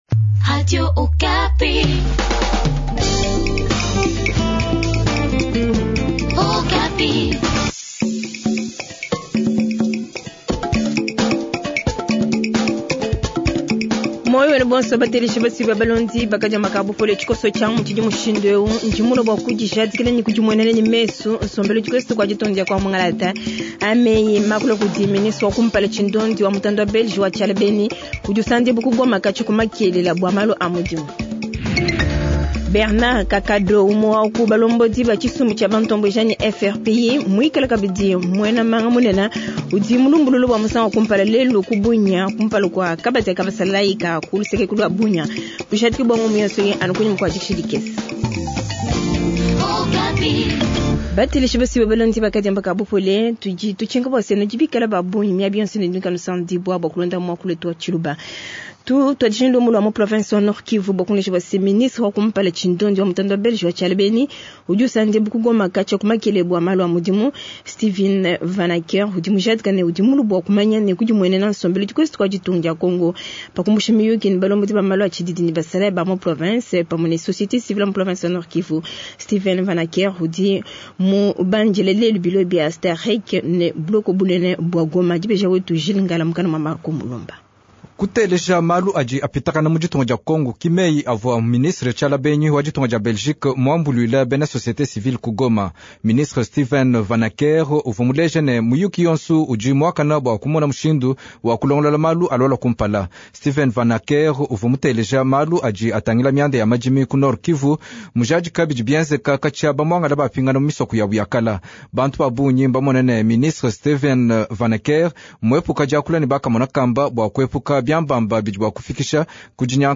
Jornal Tshiluba Soir